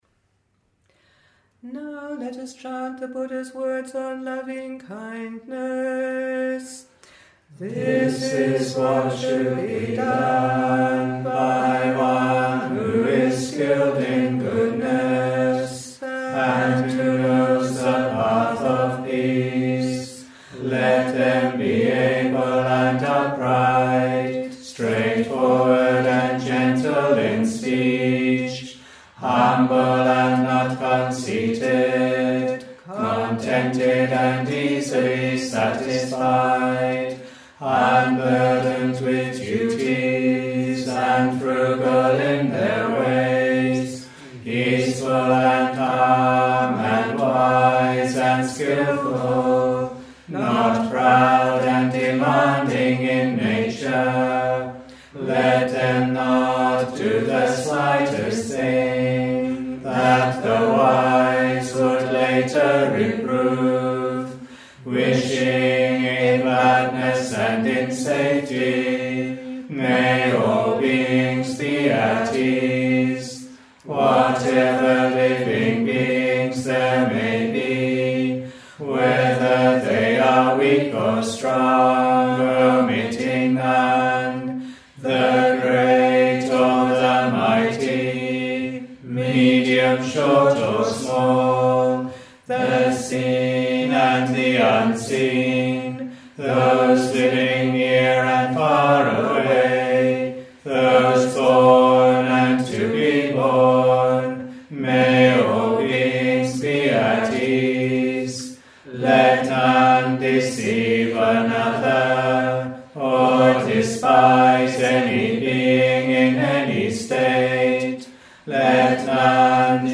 » Pali-English Chanting